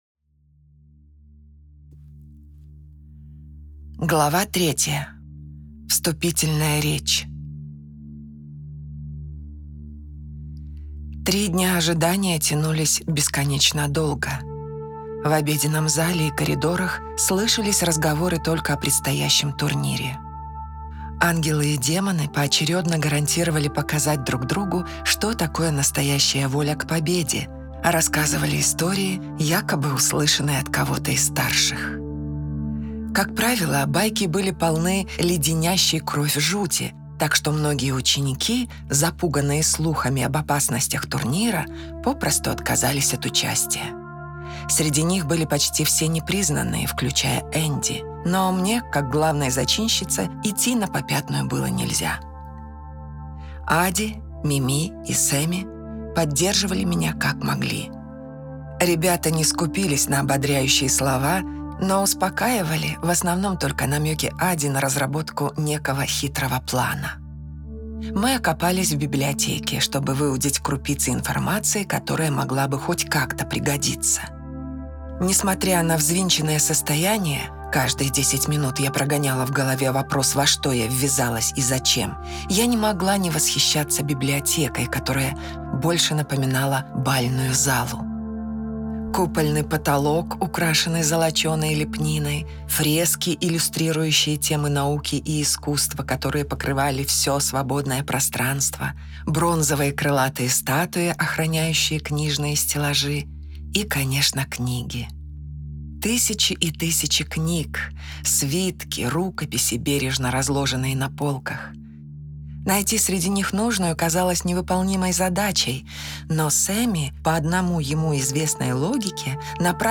С этим справилась команда студии закадровой озвучки и дубляжа 13STUDIO, вдохнувшая жизнь, страсть и романтику в новое прочтение оригинальной истории «Секрет Небес» в двух аудиоверсиях – в одноголосой озвучке и в многоголосой театрализованной версии с музыкальным и звуковым сопровождением.
Sekret_nebes_Yabloko_razdora_Mnogogolosyi_Glava_3_5min.mp3